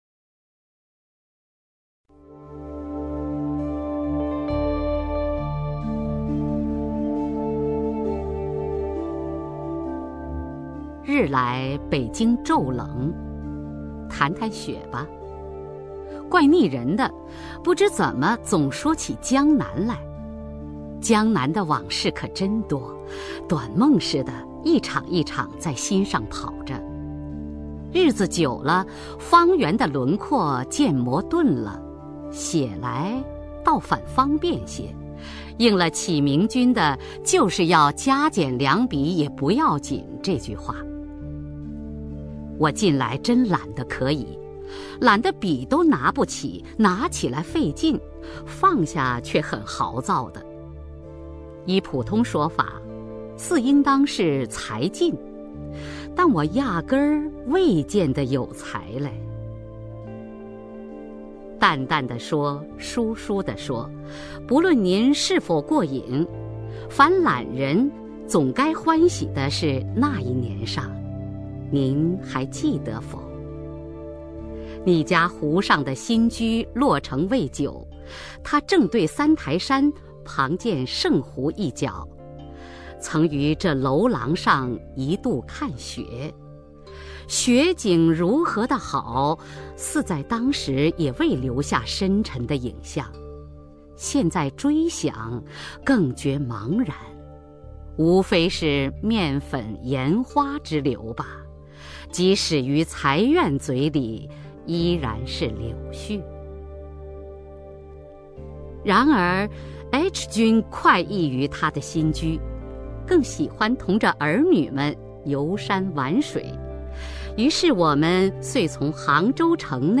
首页 视听 名家朗诵欣赏 雅坤
雅坤朗诵：《雪晚归船》(俞平伯)